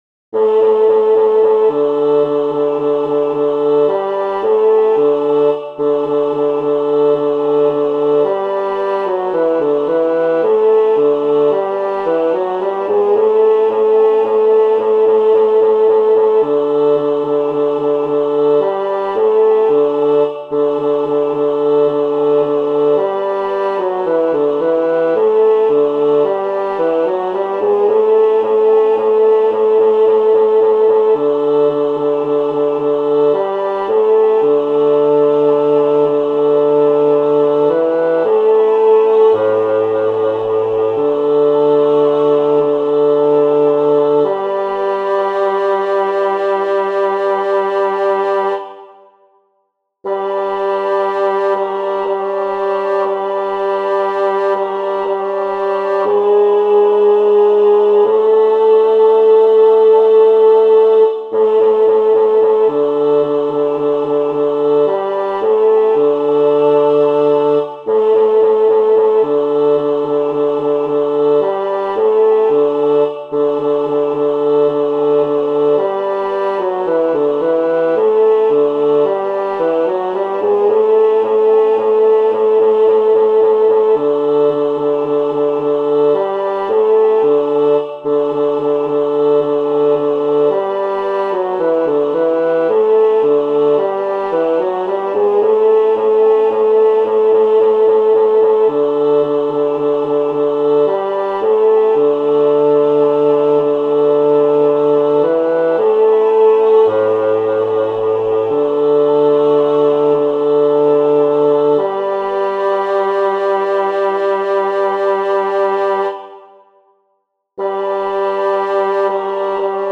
• El tempo indicado es Vivo.
Aquí os dejo los MIDI con las diferentes voces:
Bajos: cuidado con los saltos, que vais a tener muchos.